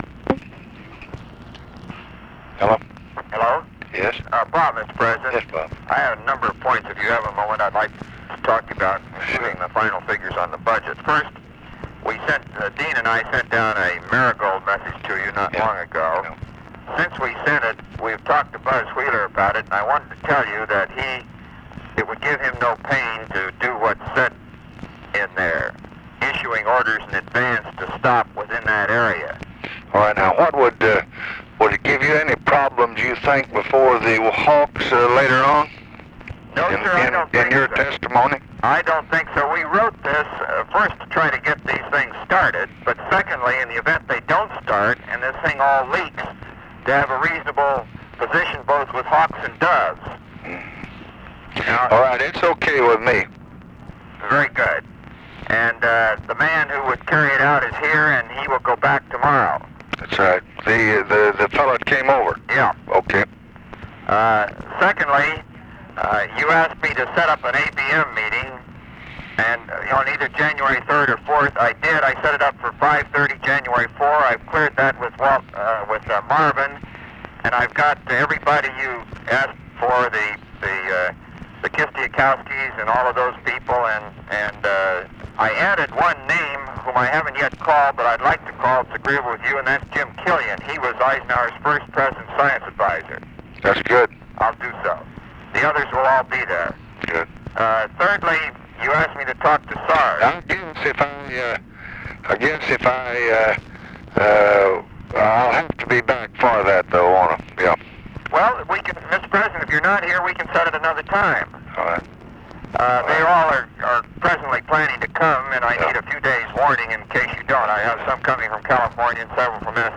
Conversation with ROBERT MCNAMARA, December 23, 1966
Secret White House Tapes